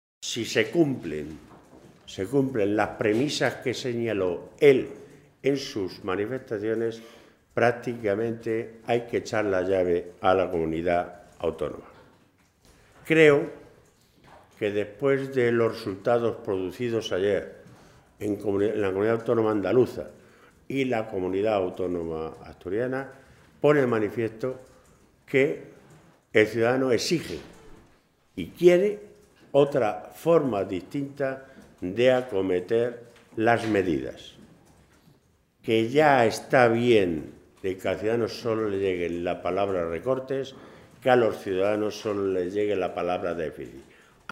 Jesús Fernández Vaquero, Secretario de Organización del PSOE de Castilla-LA Mancha
Vaquero se pronunciaba de esta manera en rueda de prensa en el Parlamento regional tras la reunión de la Mesa y la Junta de portavoces, en la que se ha fijado para el próximo jueves, día 29, coincidiendo con la huelga general convocada por los sindicatos, el próximo Pleno de la Cámara.
Cortes de audio de la rueda de prensa